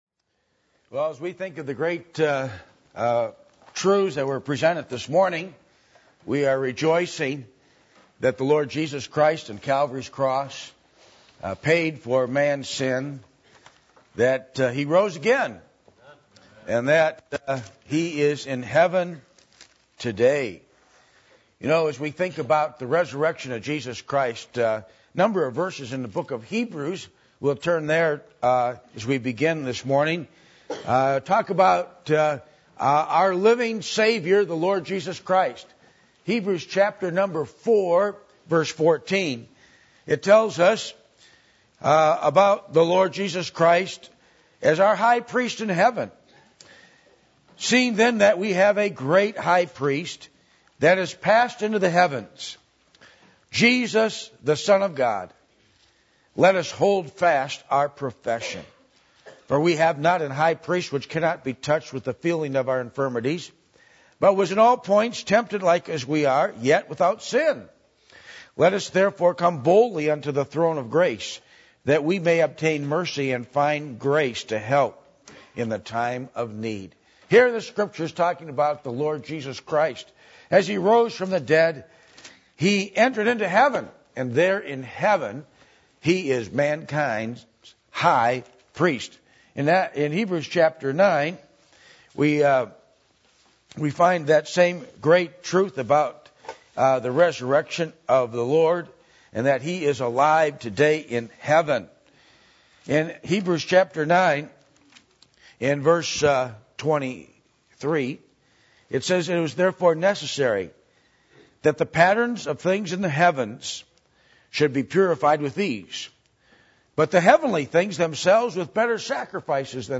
Passage: Hebrews 4:14-16 Service Type: Sunday Morning